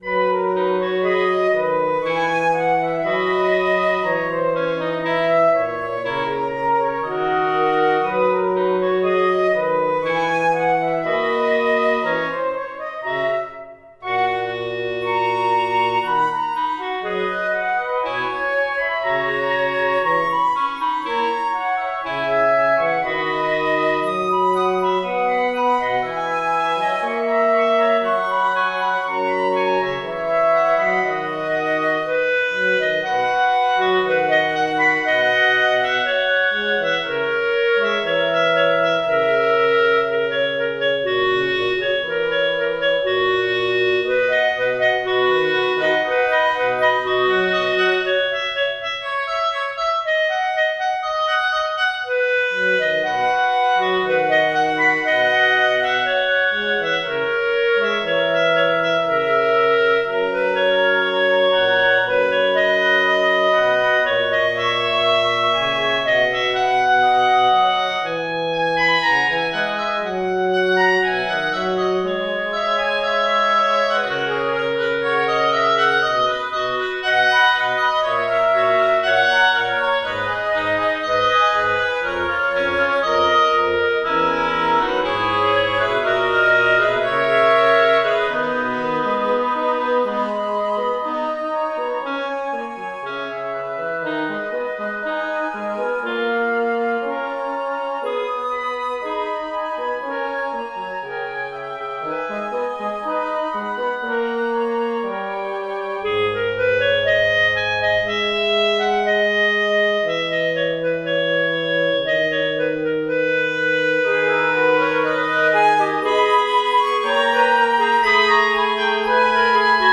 My first attempt at a woodwind quartet
I merely structured it as A B ... A B 🙂 and there are one or two harmonic attempts in it.